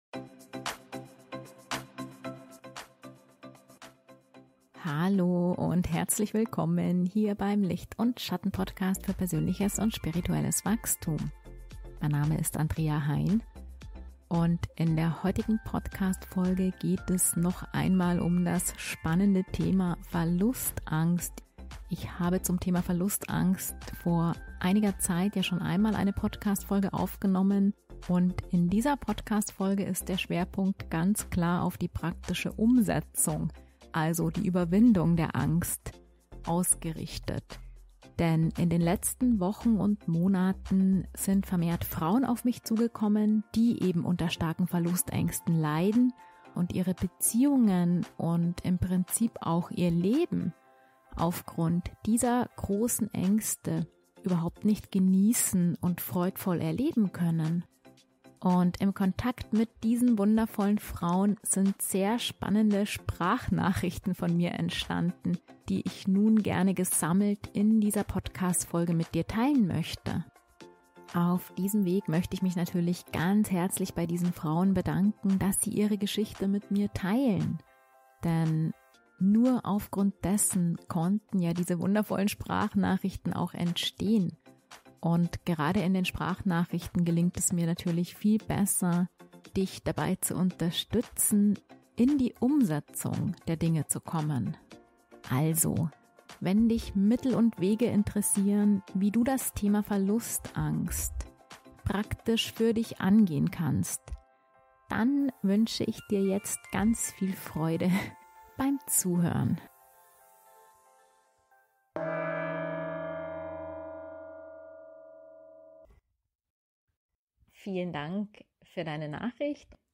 Im Kontakt mit diesen wundervollen Frauen sind sehr spannende Sprachnachrichten von mir entstanden, die ich nun gesammelt in dieser Podcastfolge gerne mit Dir teilen möchte.